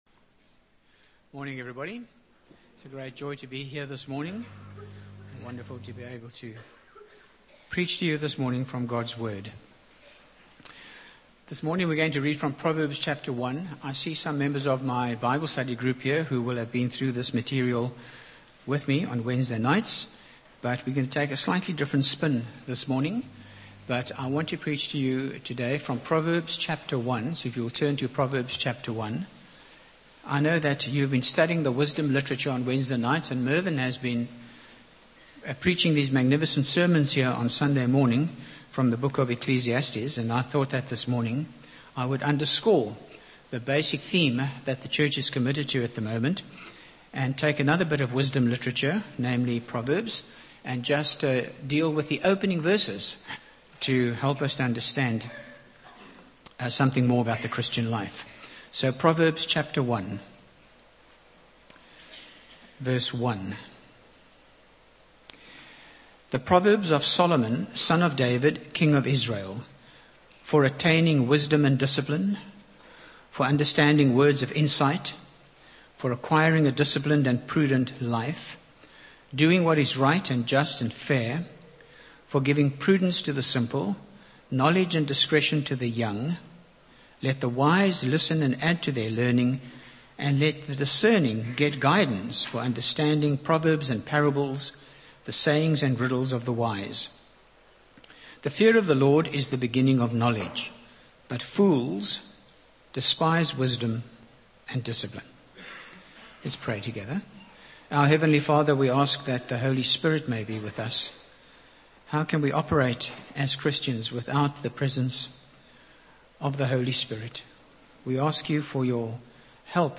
Sermons (St James)